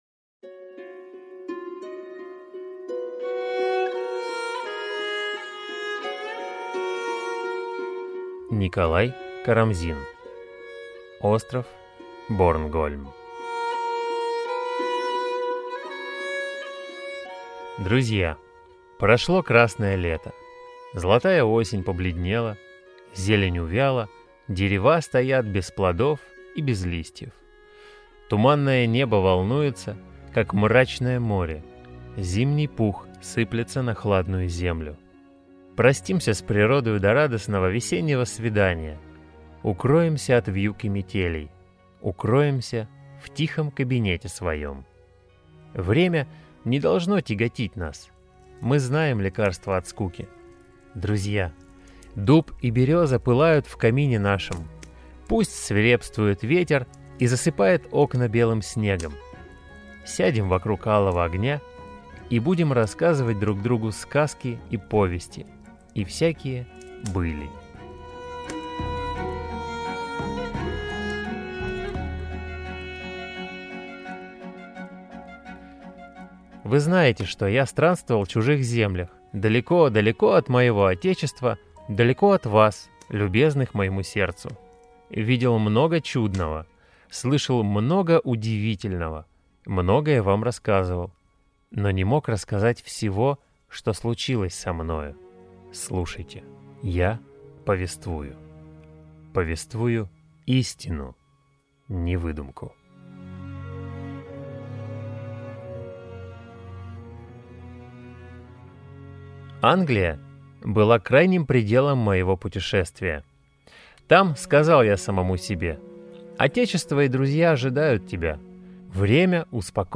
Аудиокнига Остров Борнгольм | Библиотека аудиокниг